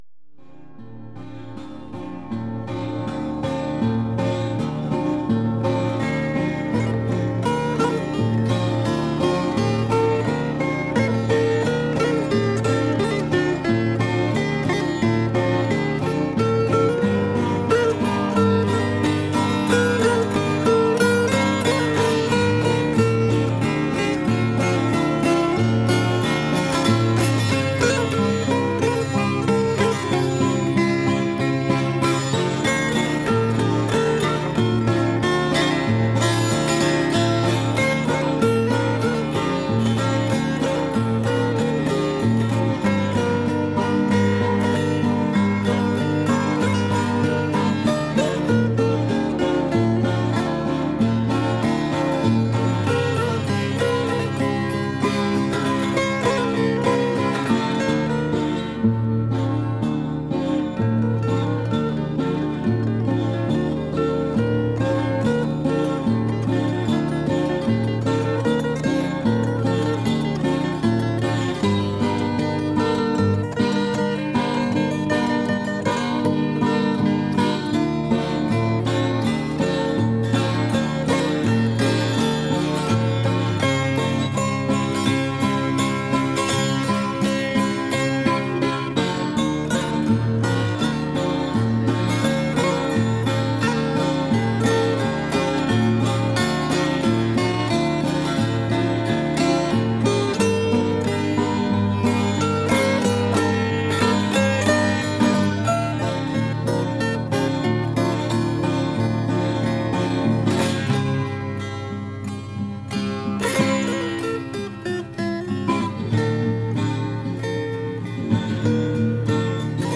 Avant-Garde Experimental